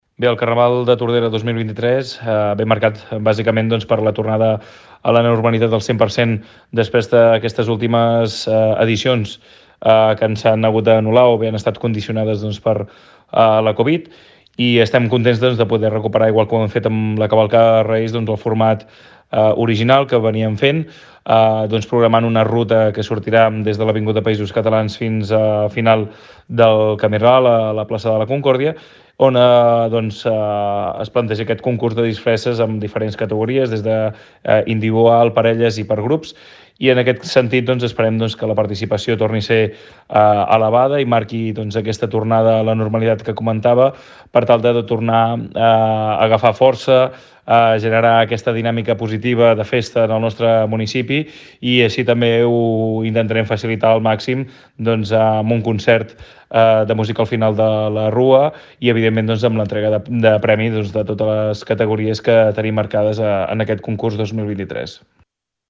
El regidor de festes, Pau Megias celebra que la rua recuperi la normalitat i convida a tothom a participar-hi.